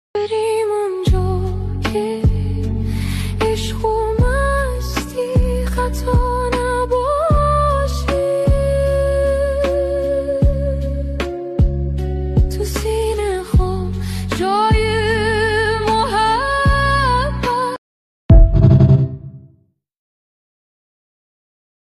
ژانر: پاپ
🎤 خواننده : صدای زن